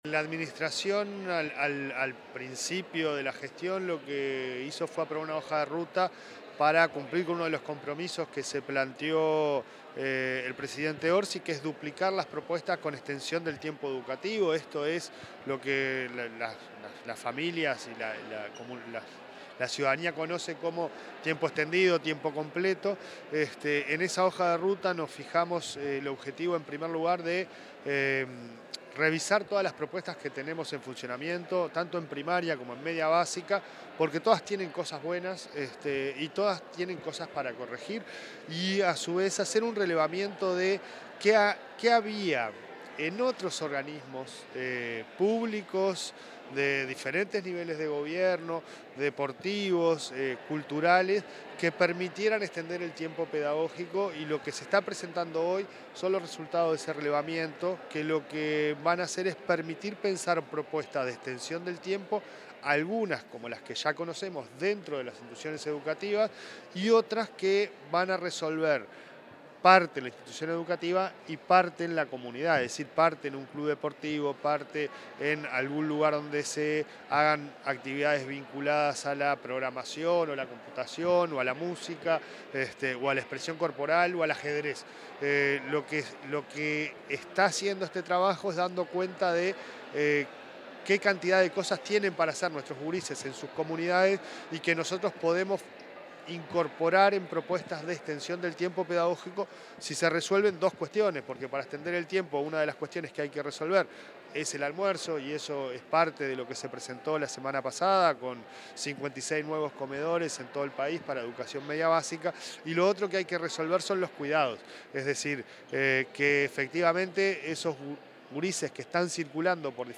Declaraciones del presidente de ANEP, Pablo Caggiani
Declaraciones del presidente de ANEP, Pablo Caggiani 08/09/2025 Compartir Facebook X Copiar enlace WhatsApp LinkedIn Tras la presentación de la hoja de ruta de Extensión del Tiempo Pedagógico, el presidente de la Administración Nacional de Educación Pública, Pablo Caggiani, diálogo con la prensa.
anep_prensa.mp3